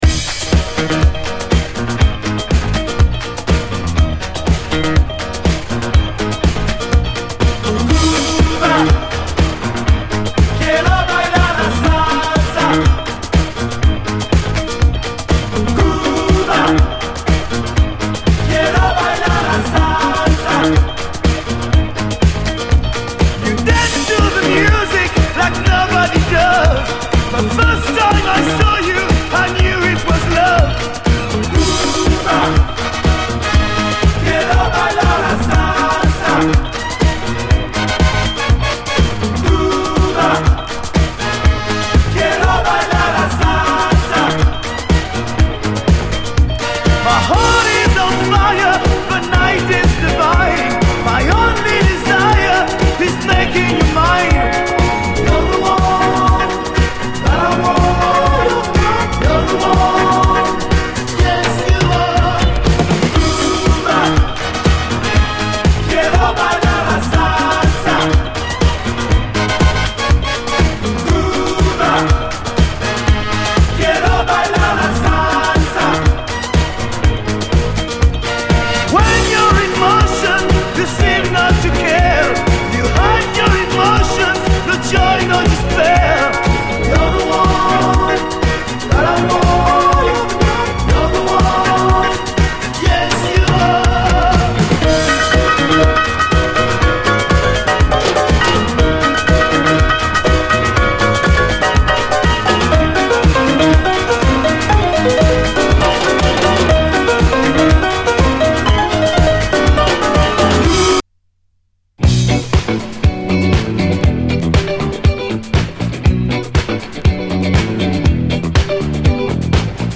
SOUL, 70's～ SOUL, DISCO
ラテン・ディスコ、マストの一枚！